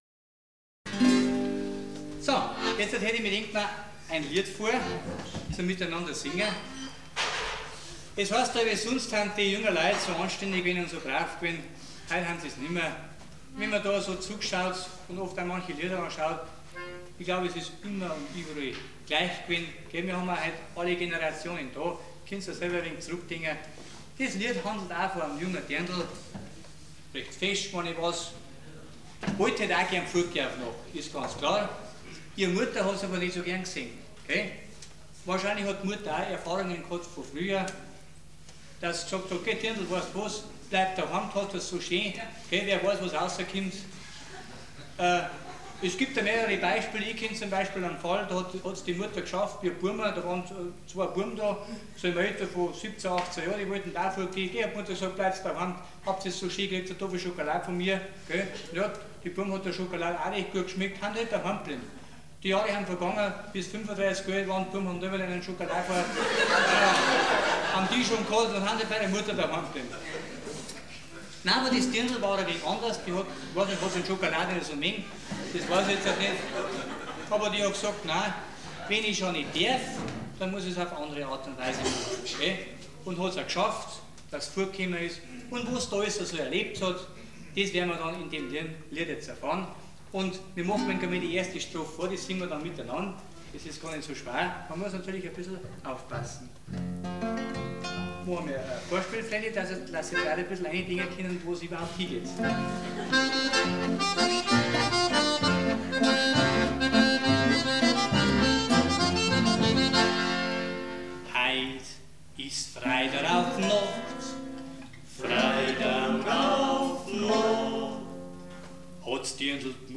Live (1993)